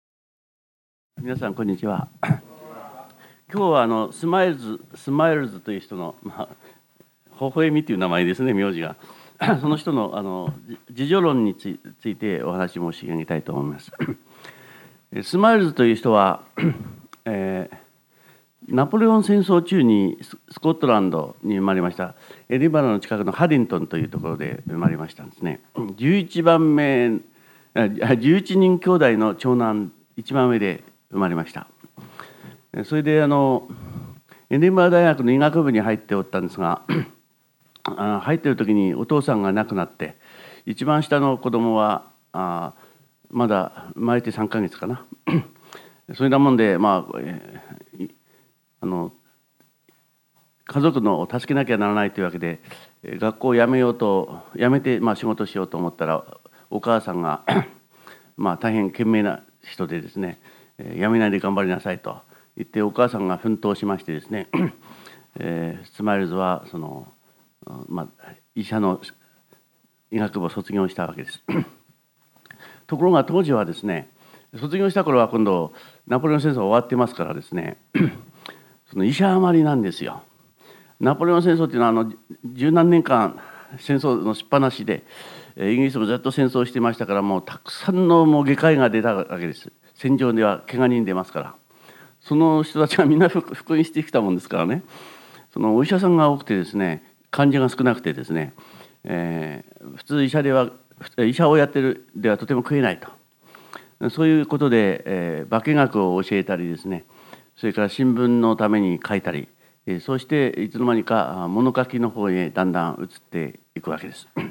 ※この 音声 は致知出版社主催の渡部昇一・知的生き方塾「先哲に学ぶ『自己修養』のすすめ」での講演を収録したもので、「渡部昇一講演録CD修養1」第2巻で収録されているものと同じ内容です。